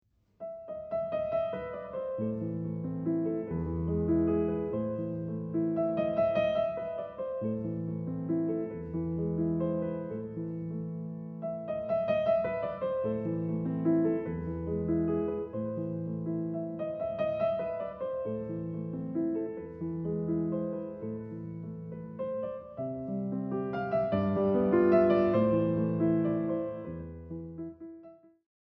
Exactly the same principle, right hand with a melody in sixteenth-notes where the left hand fills in the flow of notes when needed, is used in “Fur Elise”:
The beauty of this is that at the same time as there is melody, and movement, the gentle flourishes of sixteenth-notes also create harmonies that moves the piece forward and puts even more beauty in the music.